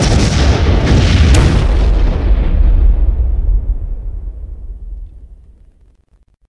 cargobaydamage.ogg